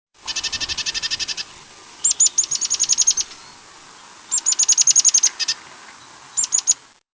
Parus wollweberi (bridled titmouse)
Central Arizona.
The normal song is a buzzing, chickadee-like sound heard in this sample.